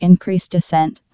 TCAS voice sound samples. ... Artificial female voice.